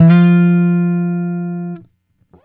Guitar Slid Octave 06-F2.wav